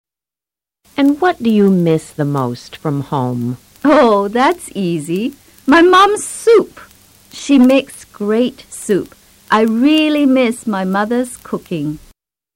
Activity 2: Este es un ejercicio de COMPRENSION AUDITIVA. Escucha el final de la conversación anterior.